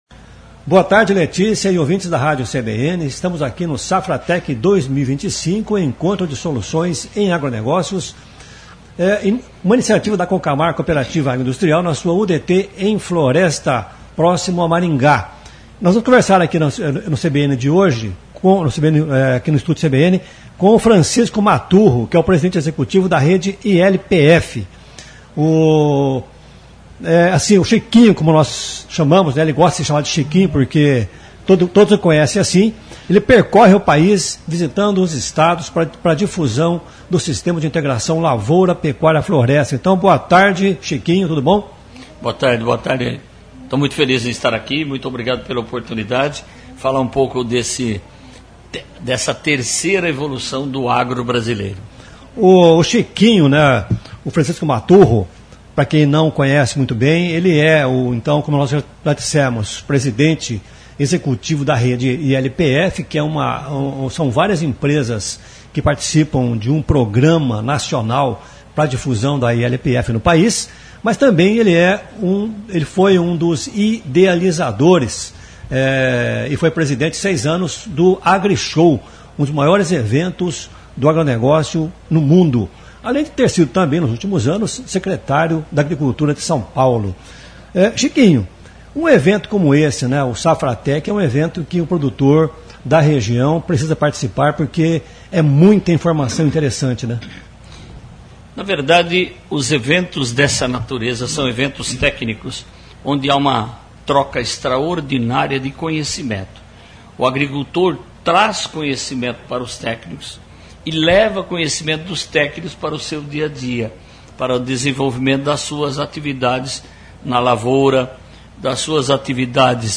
entrevista
na Safratec 2025